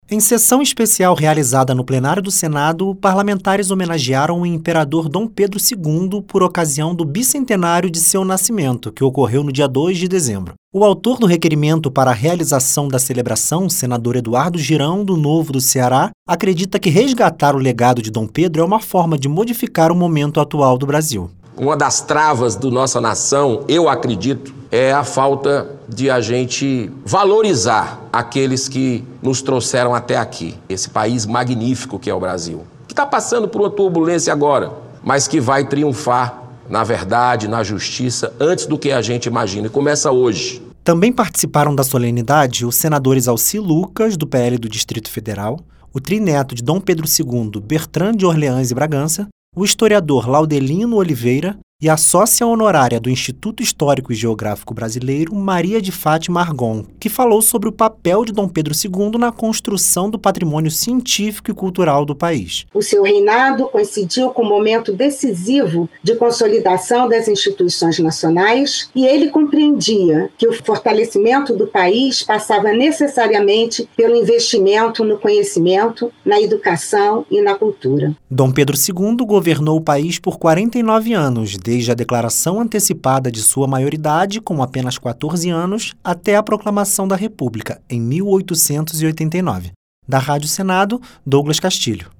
Sessão Especial